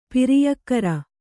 ♪ piriyakkara